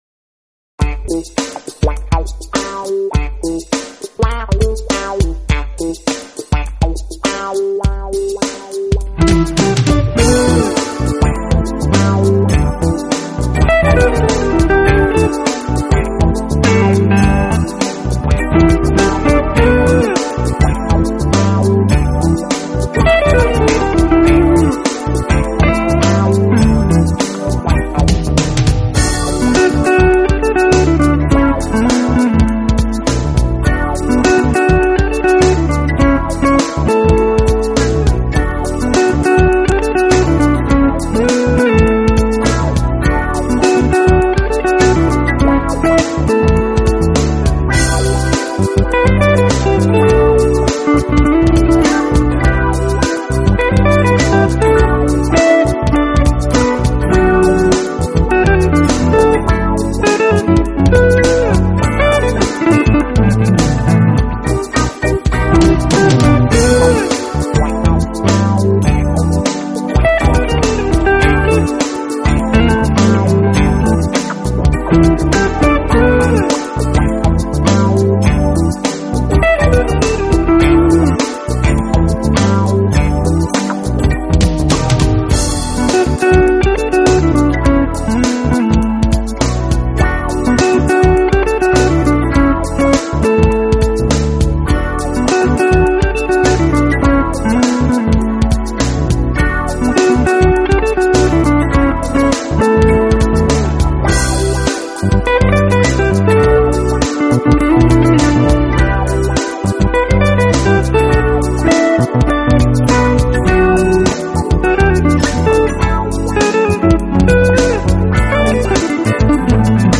演奏非常有特色，节奏欢快，旋律优美。